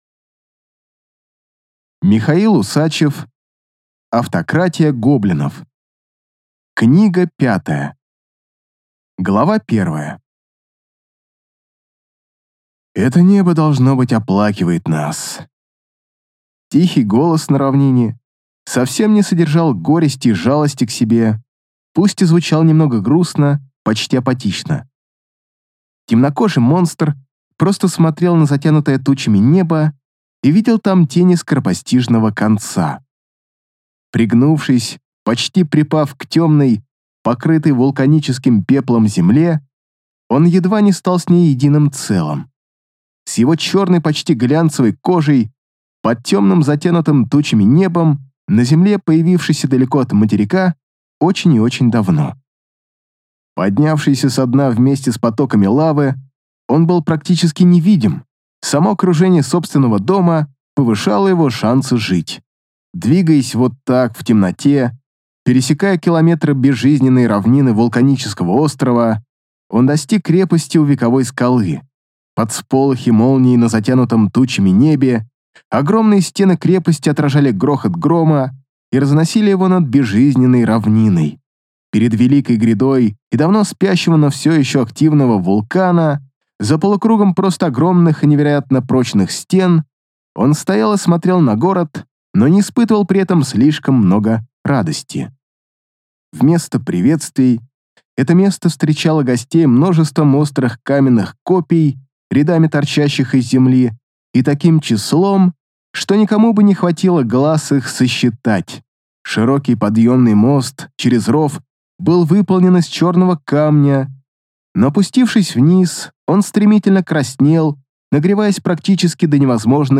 Аудиокнига Автократия гоблинов 5 (Финал) | Библиотека аудиокниг